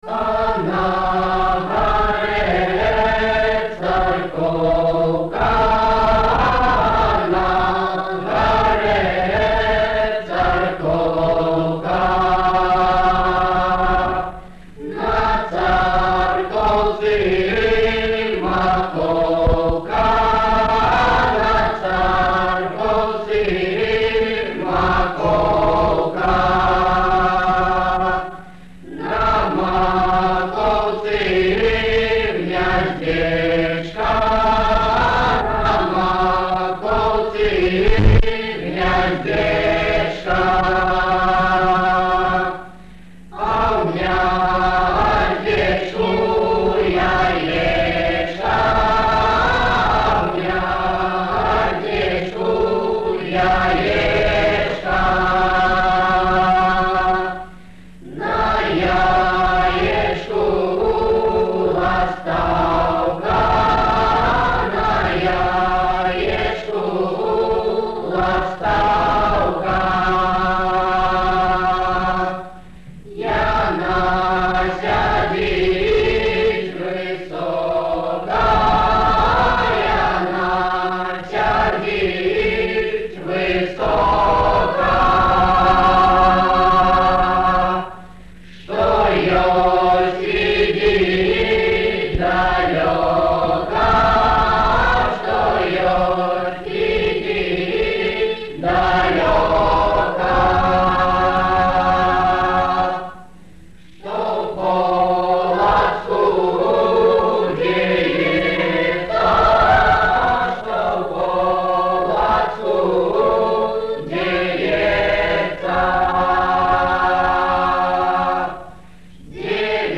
Майстроўня сьпявае "Царкоўку". Архіўны запіс.